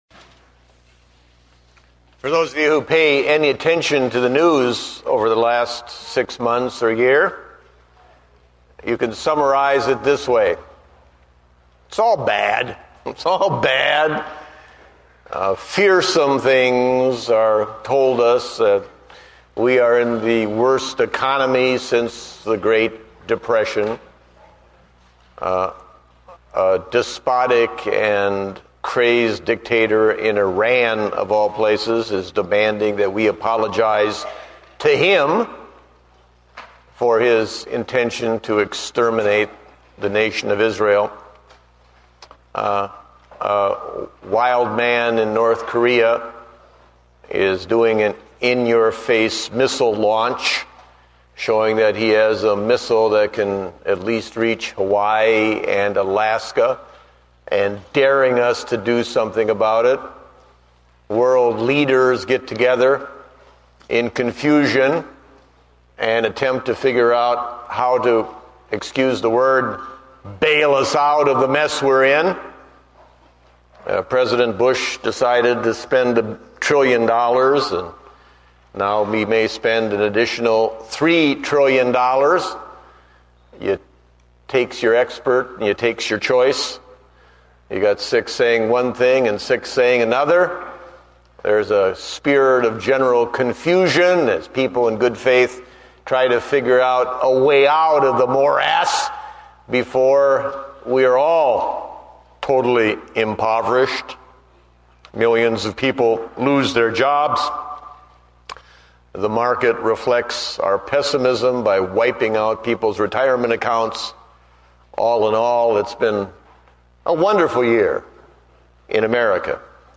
Date: April 5, 2009 (Morning Service)